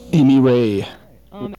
07. interview (0:01)